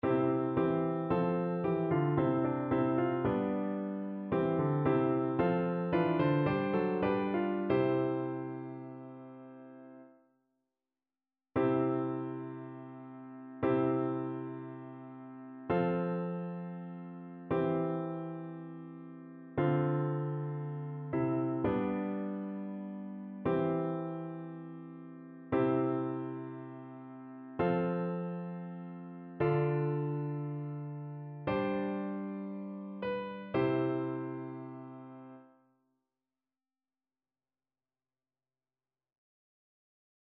Chœur
annee-abc-temps-pascal-ascension-du-seigneur-psaume-46-satb.mp3